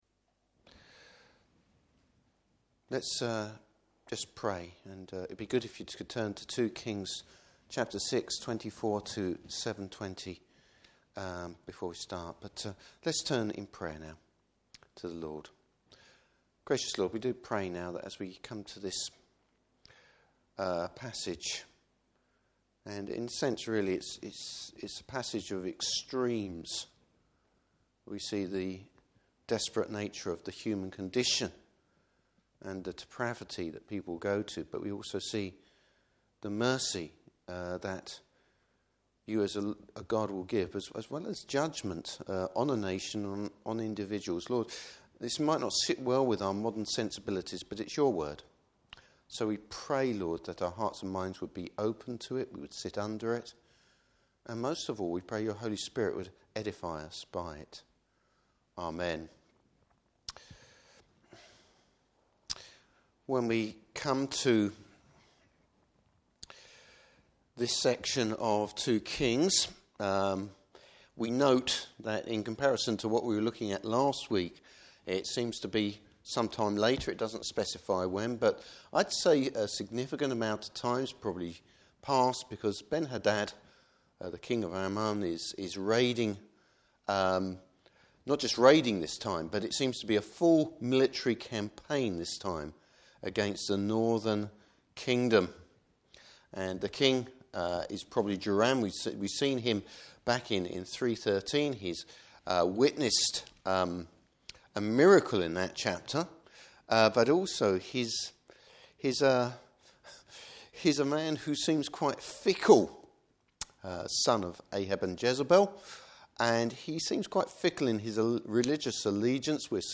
Service Type: Evening Service Bible Text: 2 Kings 6:24-7:20.